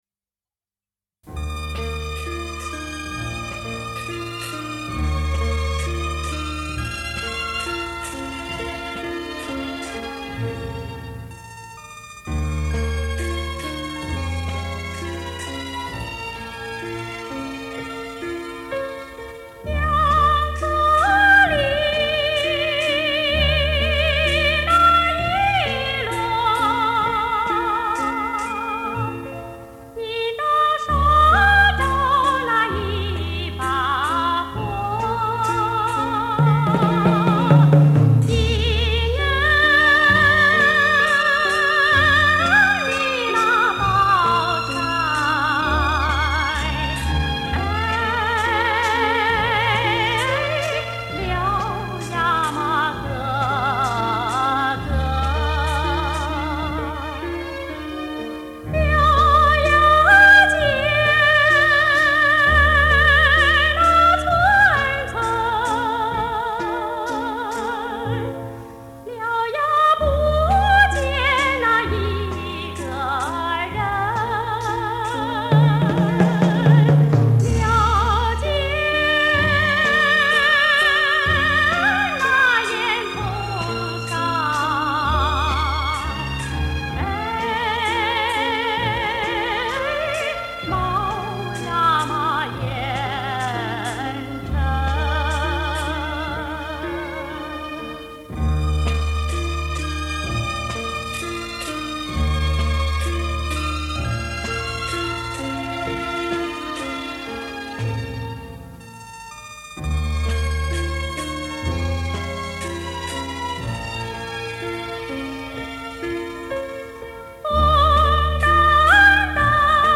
“爬山调”是广泛流行于内蒙古西部汉族聚居地区的一种山歌。
内蒙古山歌调